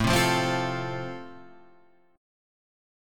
Am#5 chord {5 3 3 5 6 5} chord